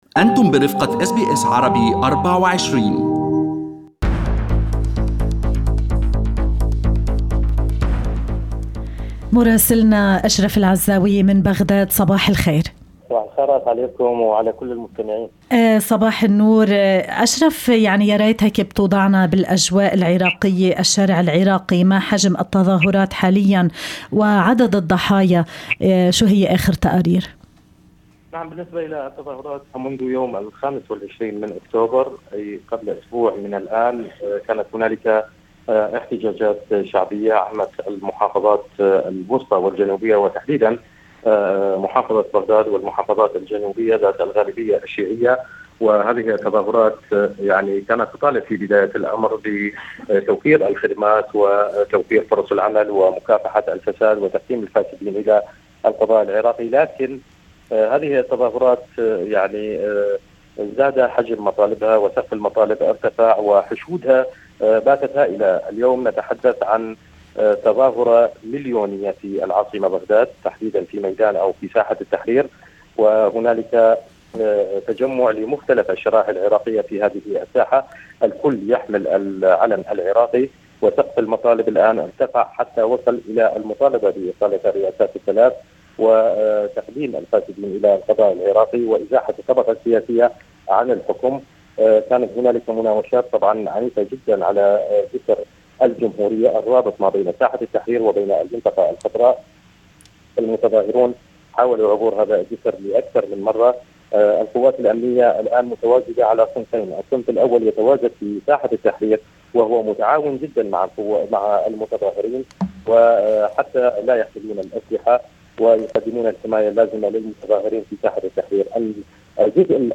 Listen to the full report from our Baghdad correspondent in Arabic above